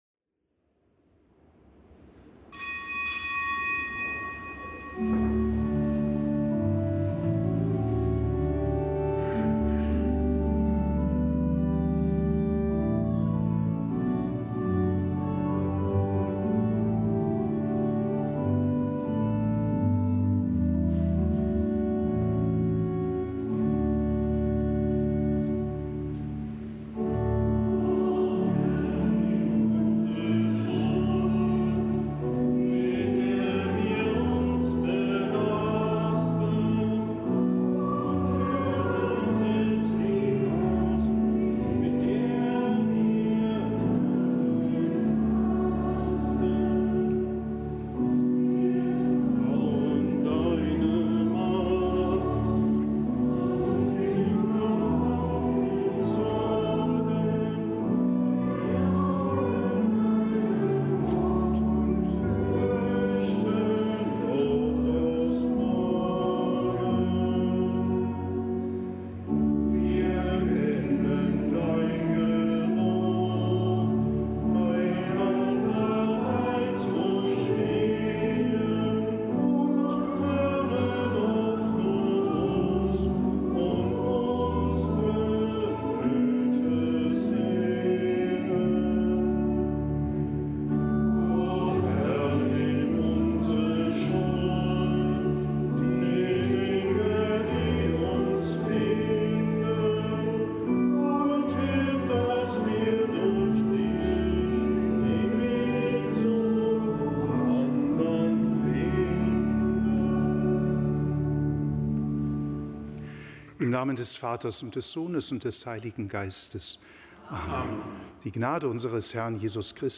Kapitelsmesse am Donnerstag der vierundzwanzigsten Woche im Jahreskreis
Kapitelsmesse aus dem Kölner Dom am Donnerstag der vierundzwanzigsten Woche im Jahreskreis, Nichtgebotener Gedenktag Heiliger Lambert, Bischof von Maastricht (Tongern), Glaubensbote in Brabant, Mär